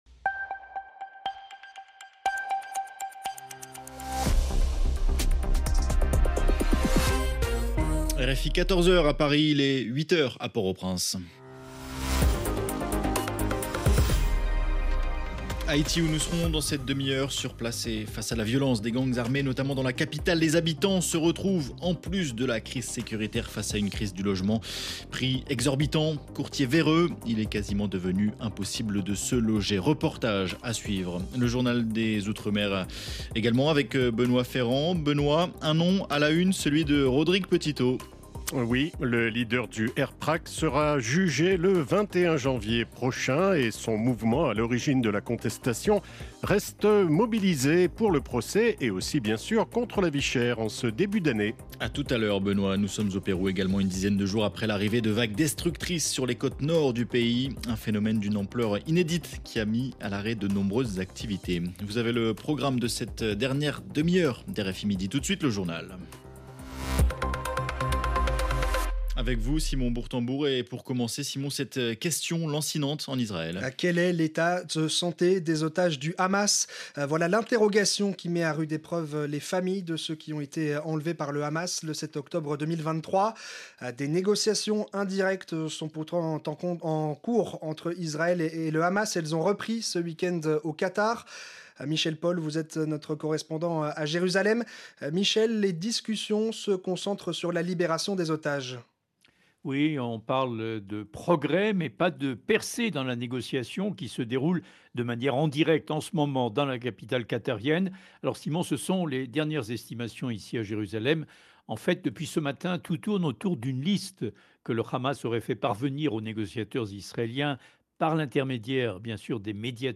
C’est pour cet auditoire que, chaque jour, RFI consacre un reportage, ou une interview, spécifiquement consacré à Haïti.